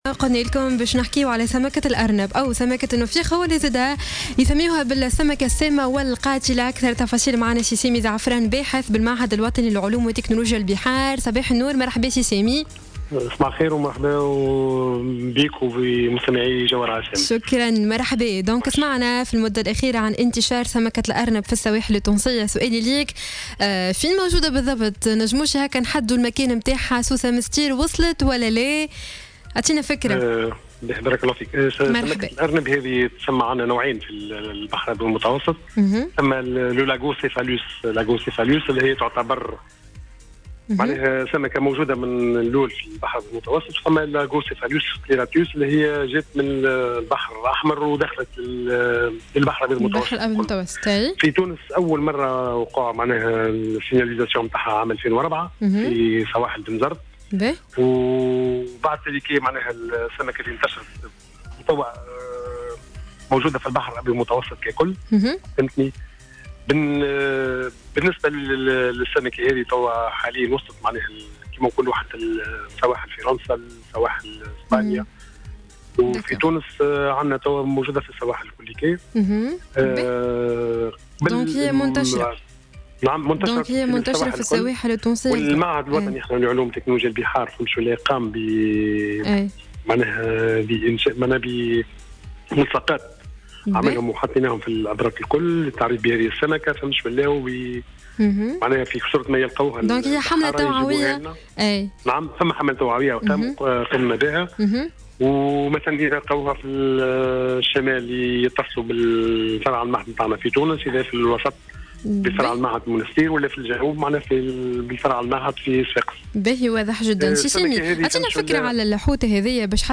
في مداخلة له اليوم على "الجوهرة أف أم"